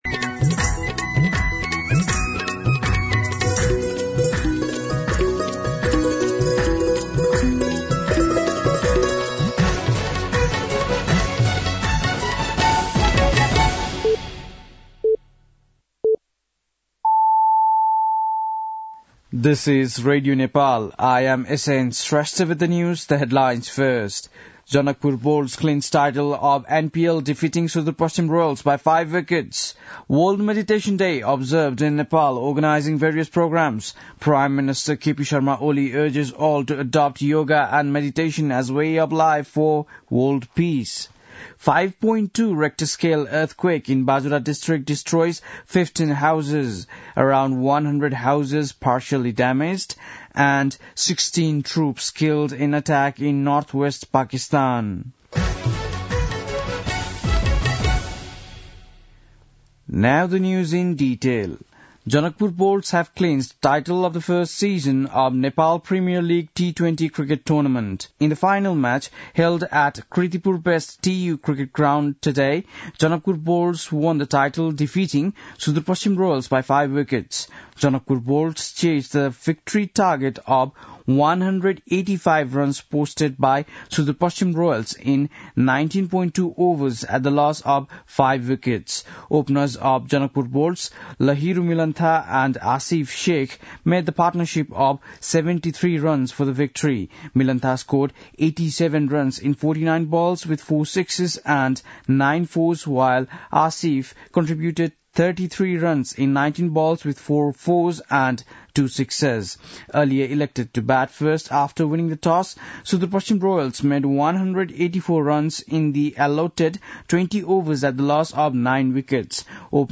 बेलुकी ८ बजेको अङ्ग्रेजी समाचार : ६ पुष , २०८१
8-PM-English-News-9-6.mp3